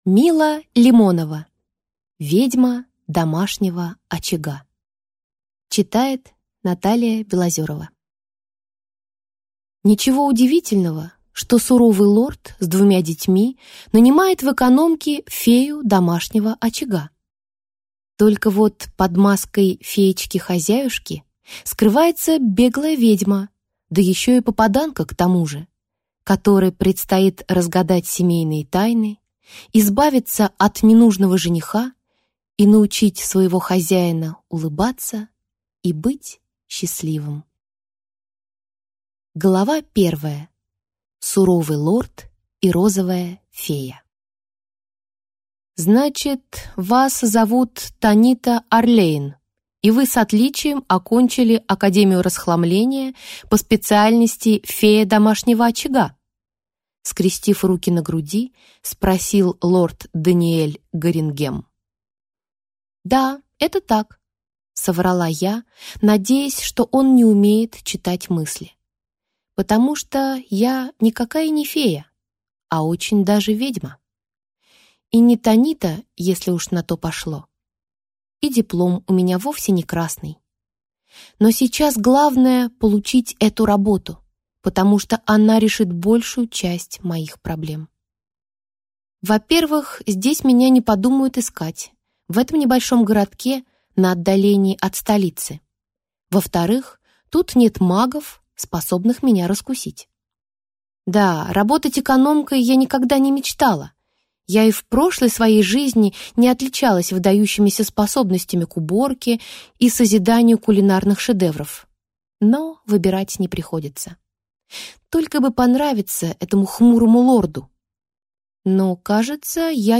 Аудиокнига Ведьма домашнего очага | Библиотека аудиокниг